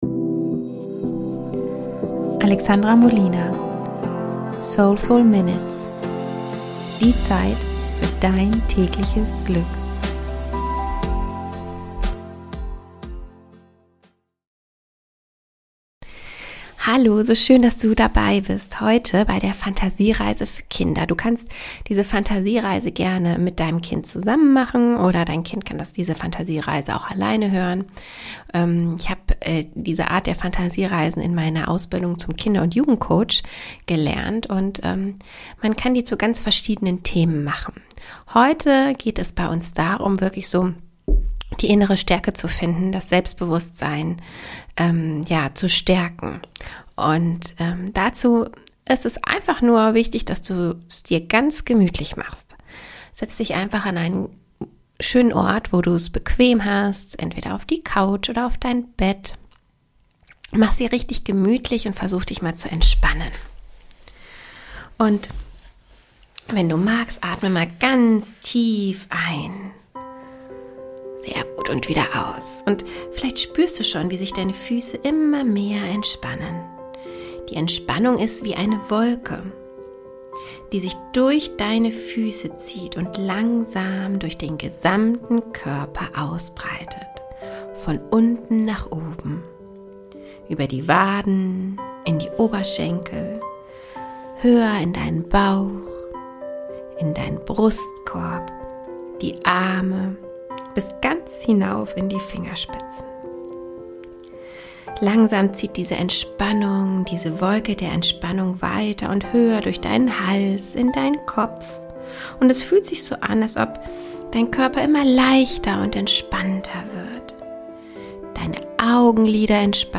Eine Fantasiereise für Kinder. Damit schon die Kleinen lernen wie wertvoll und wichtig sie sind.